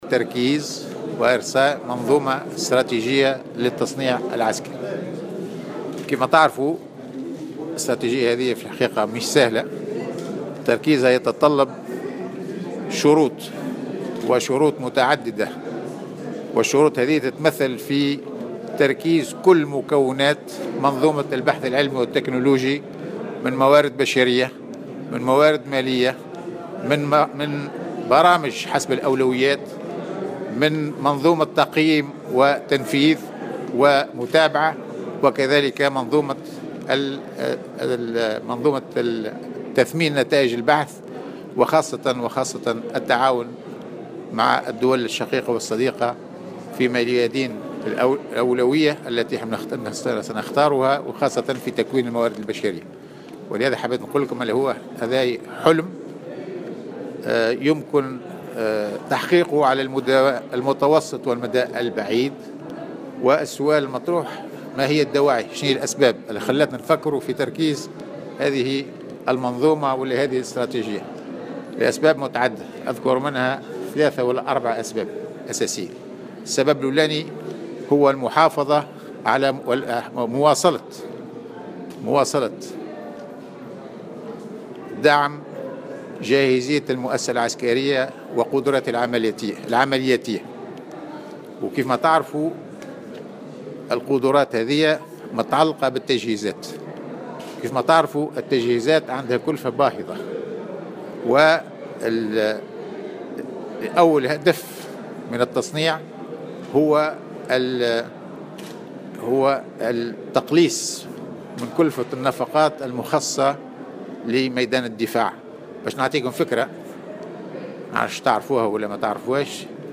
وقال إن التصنيع العسكري في تونس حلم يمكن تحقيقه على المدى المتوسط والبعيد، بتوفر عدة عوامل. وجاءت تصريحاته على هامش انعقاد الدورة 35 لمعهد الدفاع الوطني.